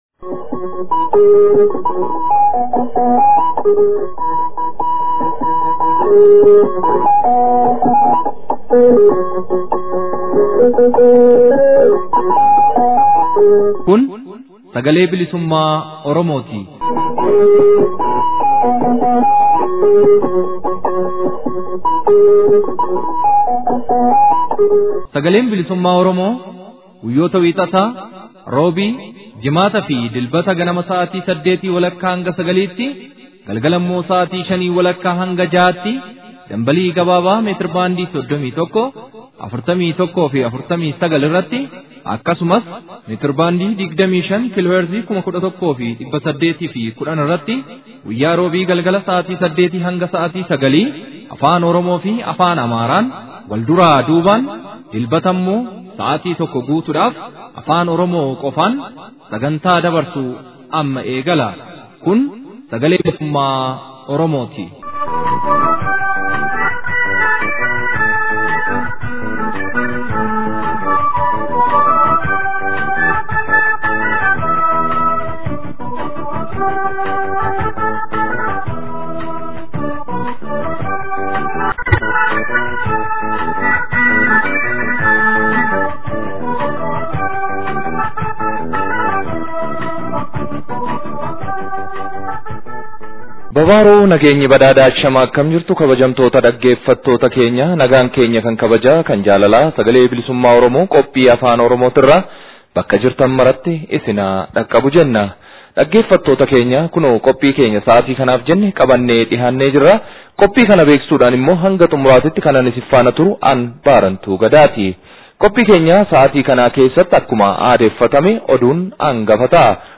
SBO Gurraandhala 28,2016. Oduu, Ijoo Dubbii ABO, Gabaasa FXG Oromiyaa keessaa fi qophiilee adda addaa haala yeroo irratti hundaawan.